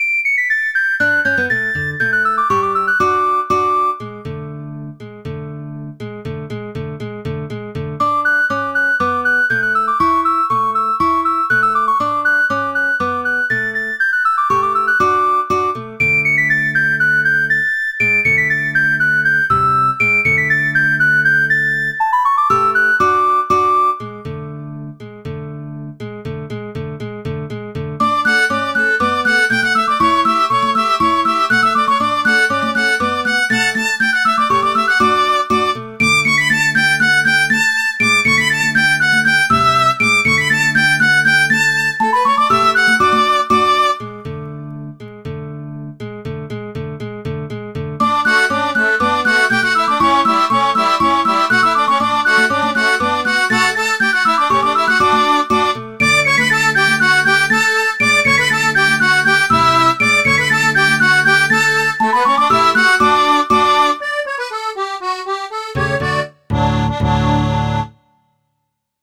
Irish drinking tune